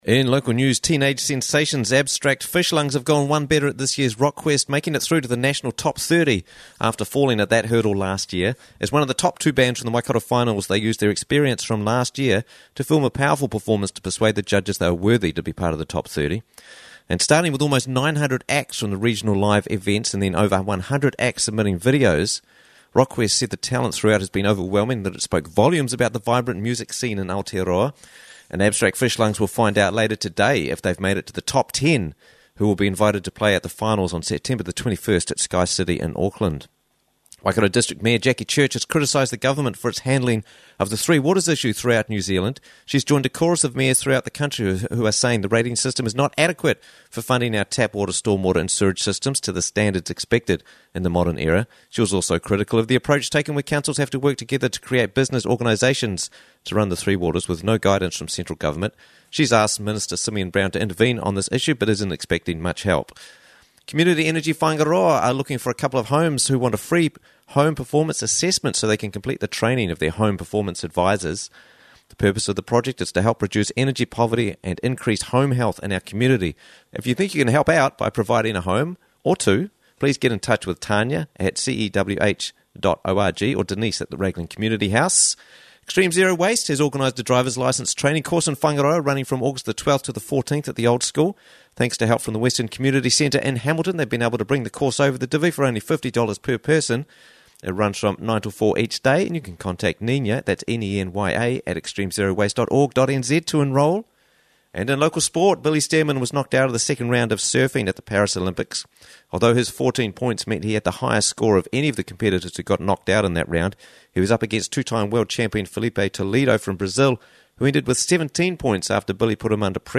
Raglan News Bulletin